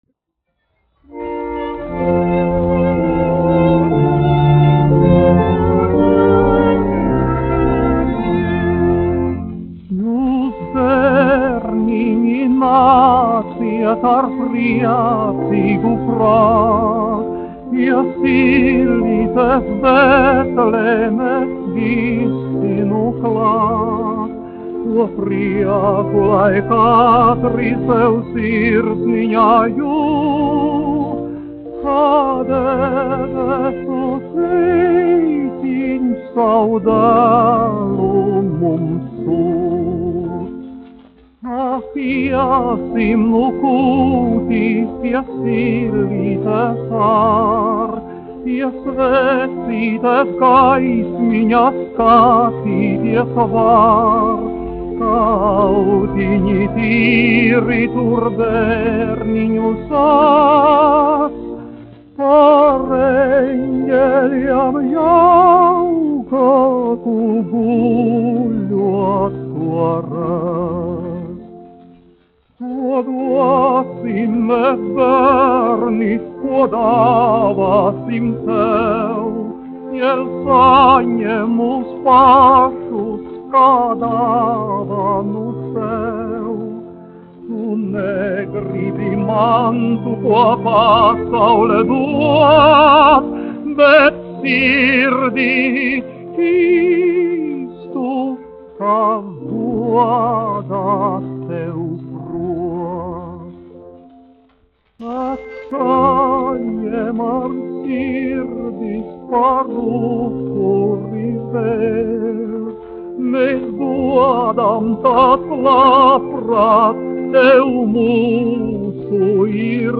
Mariss Vētra, 1901-1965, dziedātājs
1 skpl. : analogs, 78 apgr/min, mono ; 25 cm
Korāļi
Ziemassvētku mūzika
Latvijas vēsturiskie šellaka skaņuplašu ieraksti (Kolekcija)